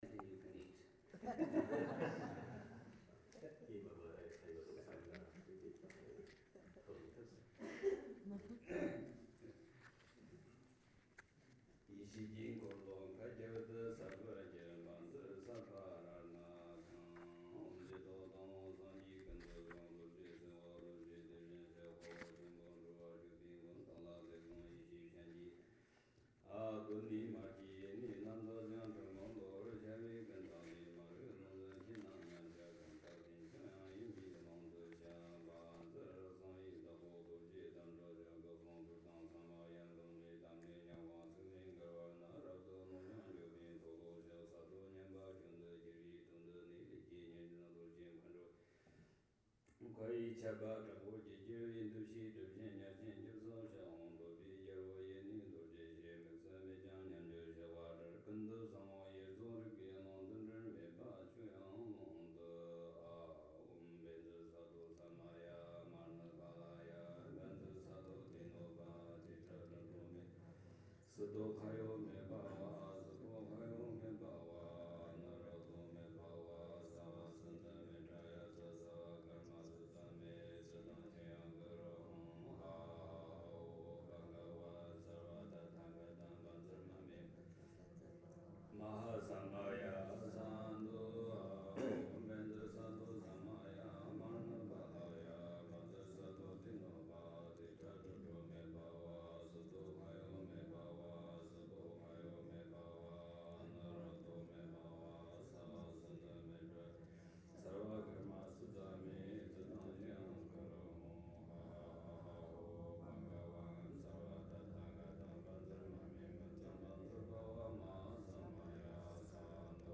Sakya Monastery, Seattle